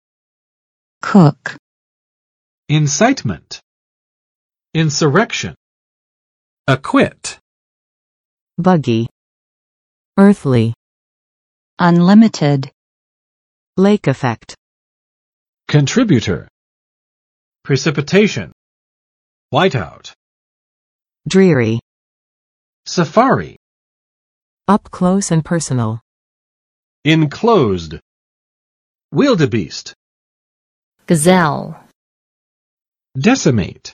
[kʊk] v.【口】发生